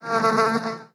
fly_buzz_flying_03.wav